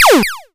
Звук одиночного выстрела лазерной пушки